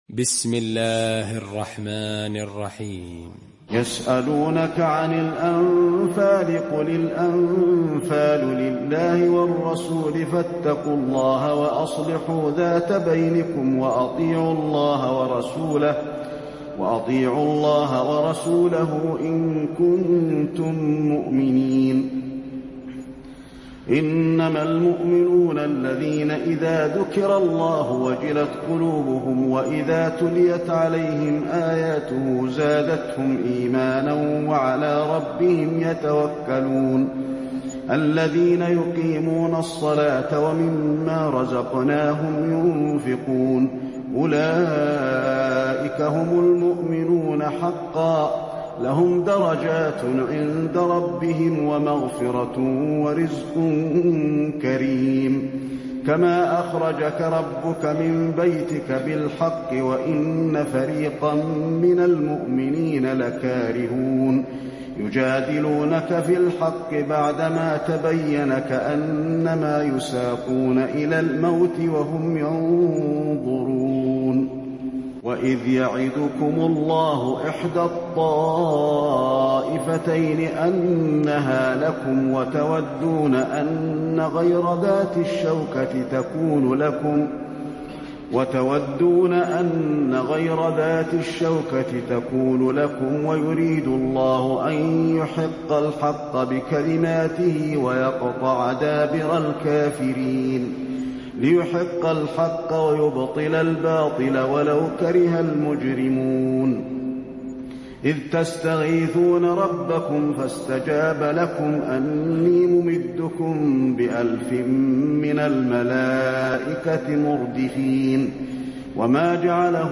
المكان: المسجد النبوي الأنفال